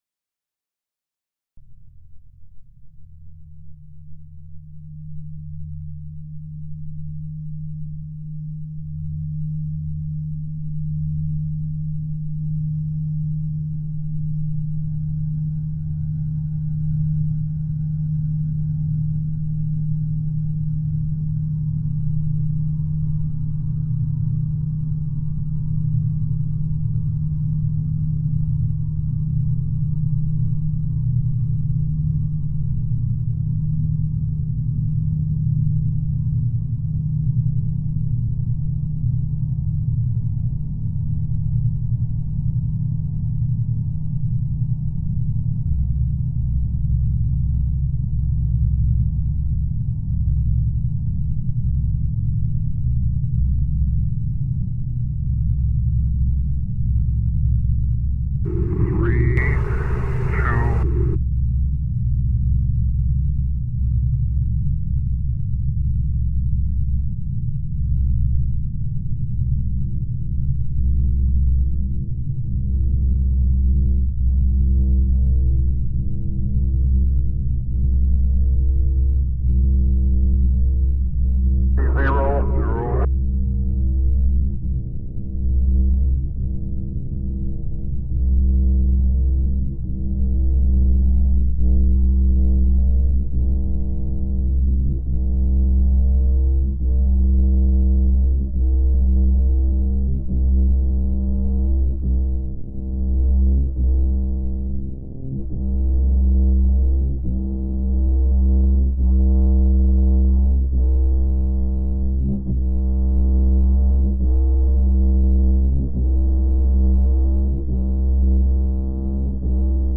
The piece simulates by its structure the liftoff process; a rhythmical machine sound goes up, grows complicated and replicates itself. After it reaches a peak it goes down, keeping up the industrial volume cycled.